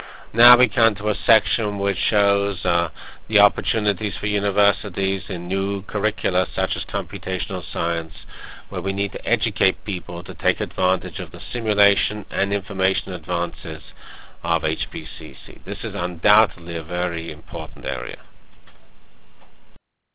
From Master Foilset for HPC at the Crossroads Keynote HPCS95 Symposium -- July 10-12 Montreal Canada.